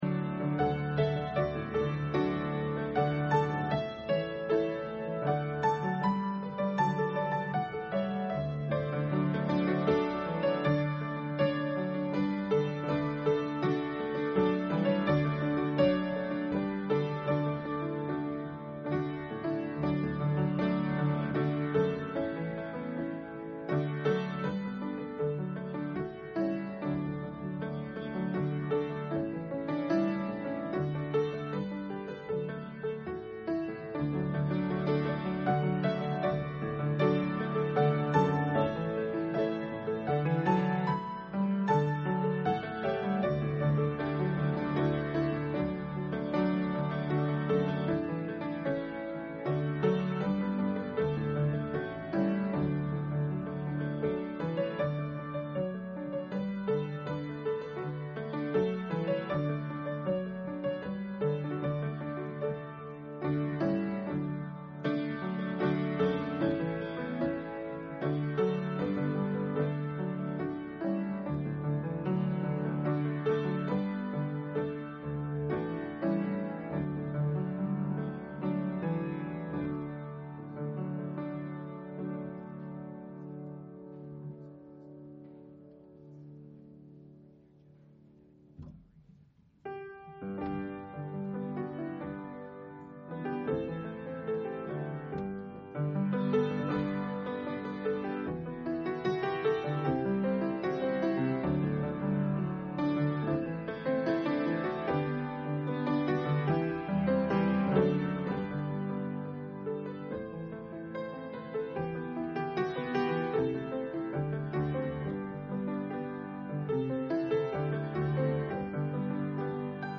Psalm 98 Service Type: Sunday Afternoon Bible Text